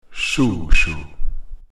Shoo-shoo